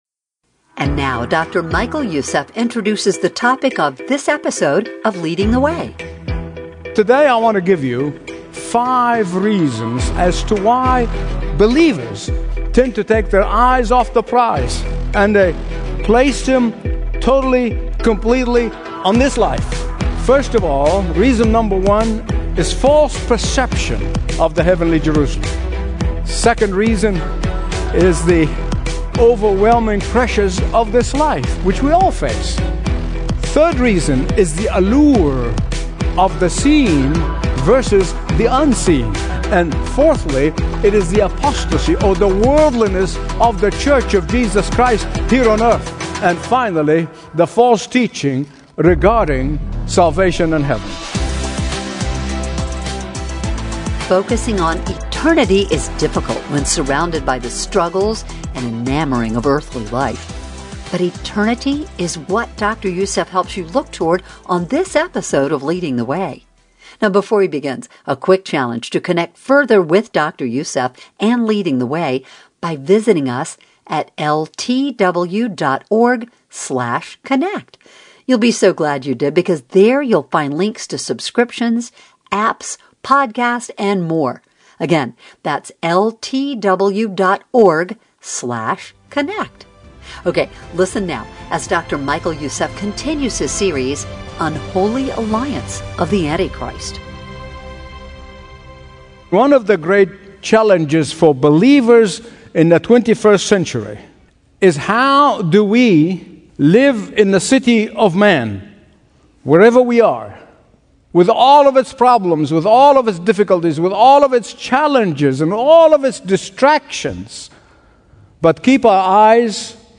Listen to Dr. Michael Youssef's Daily Teaching on Unholy Alliance of the Antichrist (Part 5) in HD Audio.